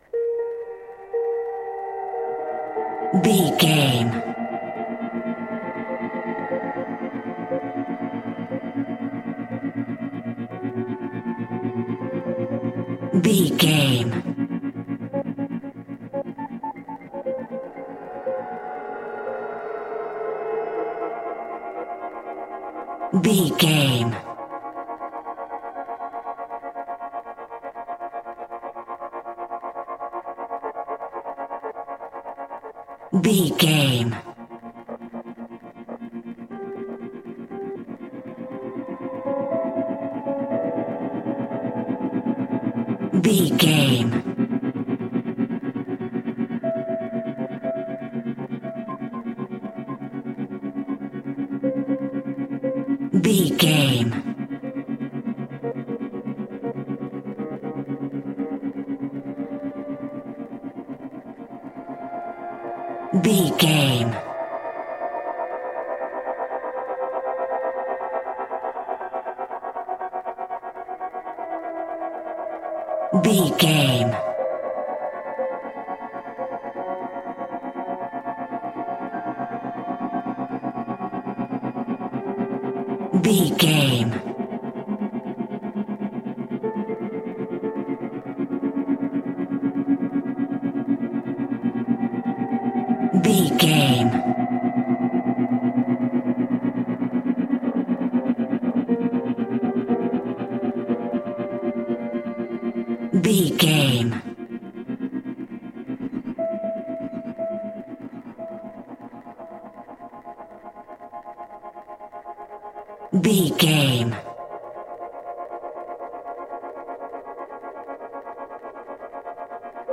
Atonal
scary
ominous
dark
suspense
eerie
piano
synthesiser
horror
ambience
pads
eletronic